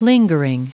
Prononciation du mot lingering en anglais (fichier audio)
Prononciation du mot : lingering